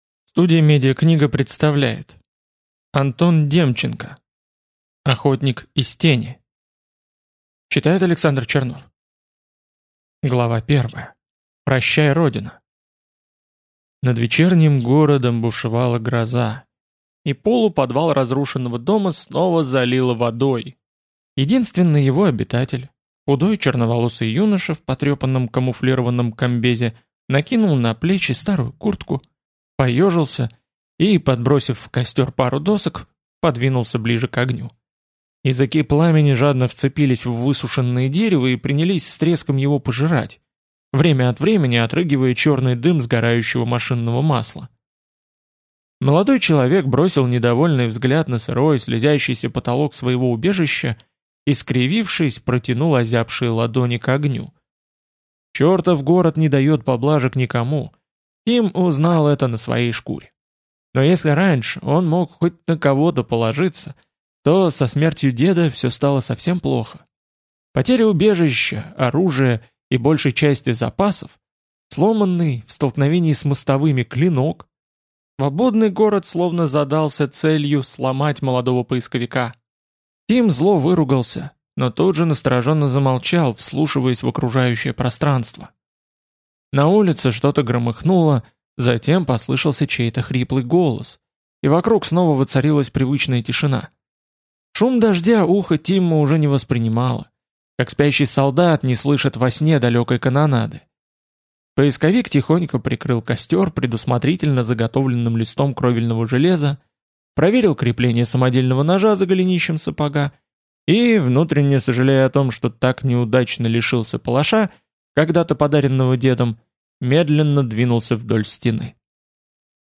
Аудиокнига Охотник из Тени | Библиотека аудиокниг